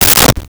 Close Medicine Cabinet Door
Close Medicine Cabinet Door.wav